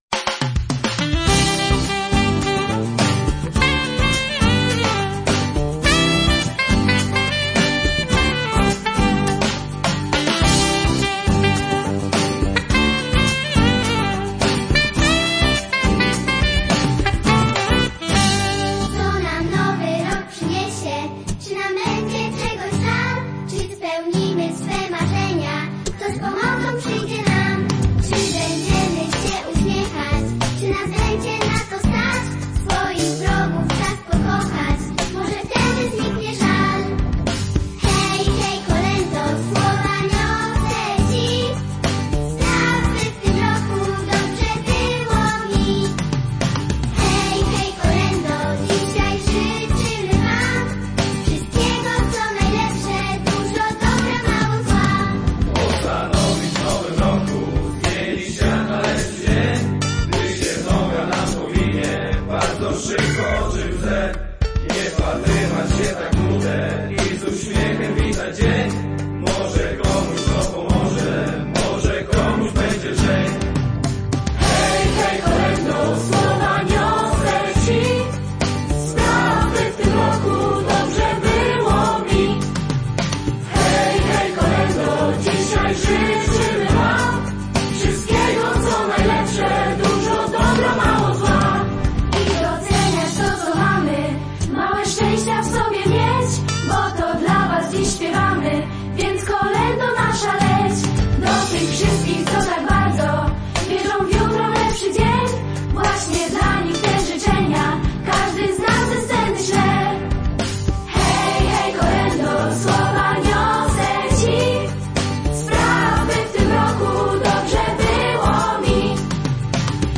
Wspólne kolędowanie rewii i szczypiornistów
Bardzo pięknie, wpada w ucho.